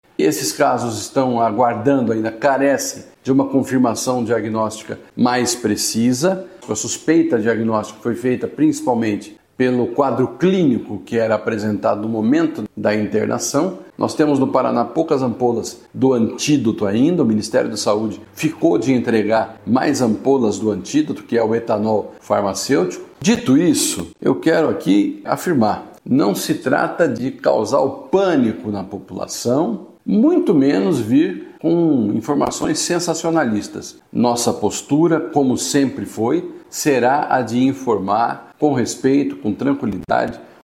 O secretário da Saúde do Paraná, Beto Preto, falou que o estado aguarda o resultado dos exames e também o envio do antídoto utilizado no tratamento de intoxicação por metanol, pelo Ministério da Saúde.
SONORA-–-CASOS-SUSPEITOS-METANOL-1-SP.mp3